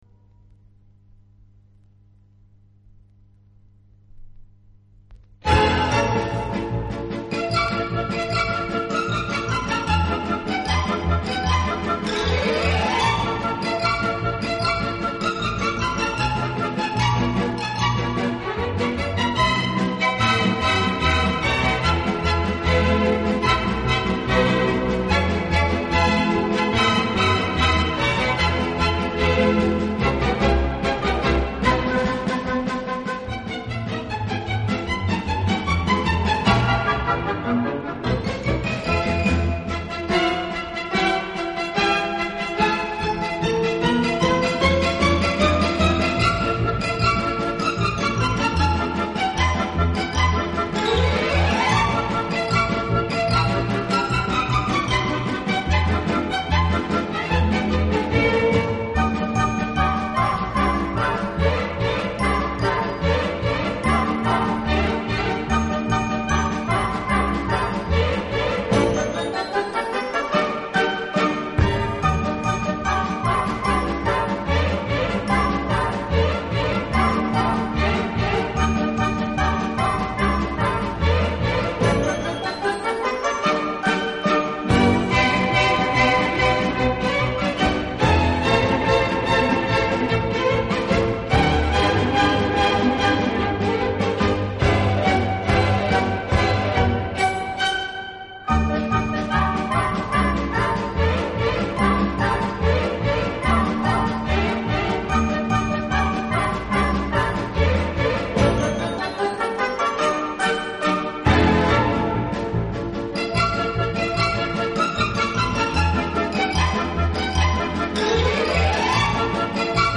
乐队的弦乐柔和、优美，极有特色，打击乐则气度不凡，而手风琴、钢琴等乐器